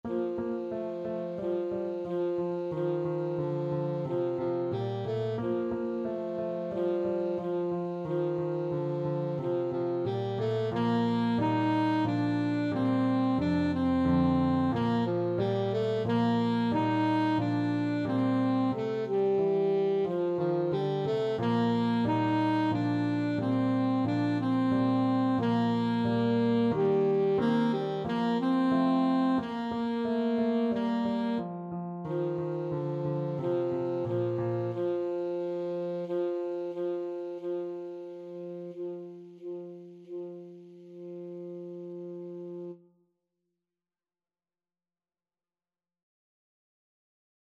Alto Saxophone
A beginners piece with a rock-like descending bass line.
March-like = 90
Pop (View more Pop Saxophone Music)